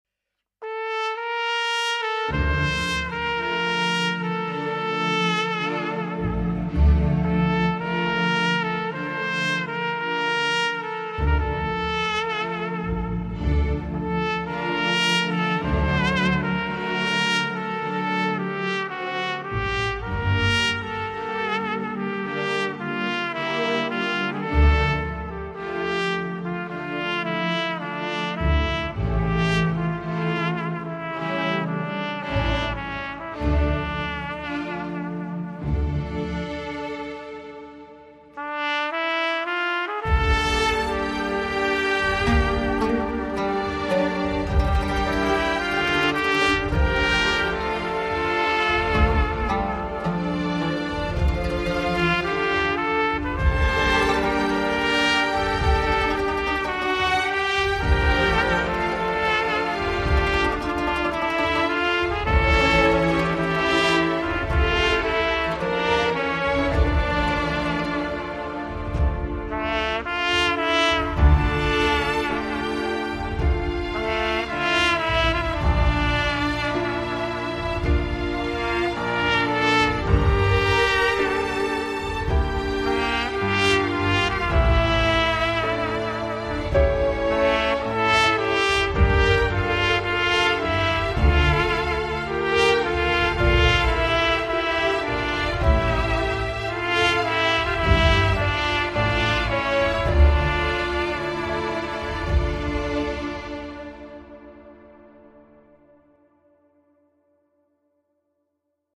زیبا و حماسی خدا اجرتان دهد